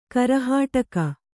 ♪ karahāṭaka